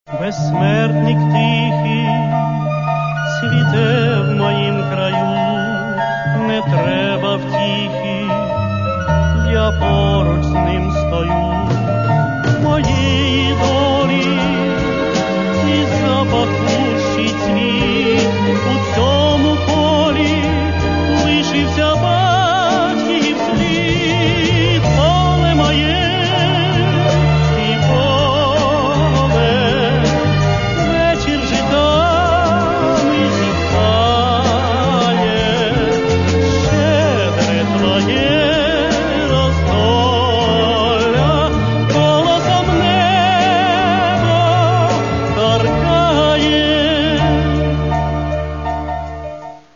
Каталог -> Естрада -> Співаки
Це можна назвати українським естрадним ретро.
Чистий, прозорий, легкий.